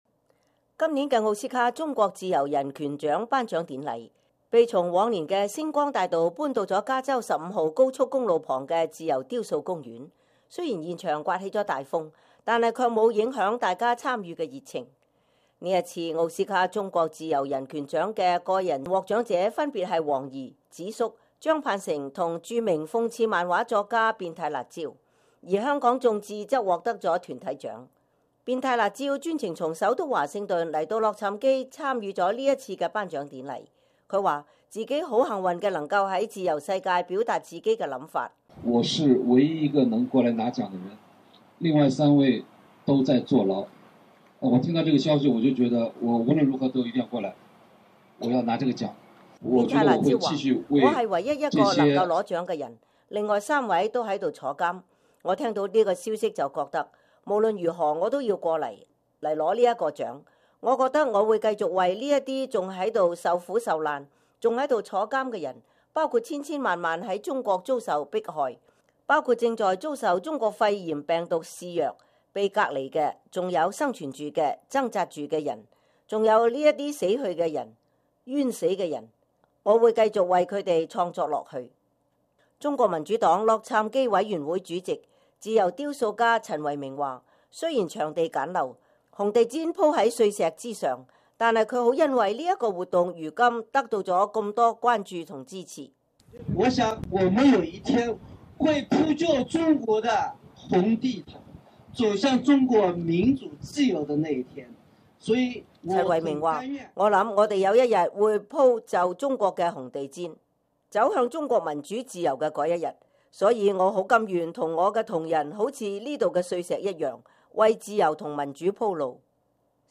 2月9日，在洛杉磯北部小鎮耶莫（Yermo）的自由雕塑公園，中國民主黨全國委員會和中國民主黨洛杉磯委員會，舉辦第7屆奧斯卡中國自由人權獎頒獎典禮。
儘管現場刮起了大風，但卻並沒有影響大家參與的熱情。